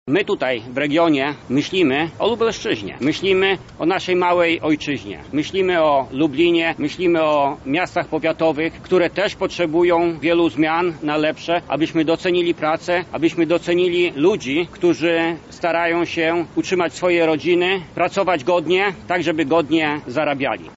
Dziś (6.11) na placu Litewskim opowiedzieli o swoich postulatach.
• mówi Jacek Bury, szef zarządu regionu Polska 2050 Lubelskie.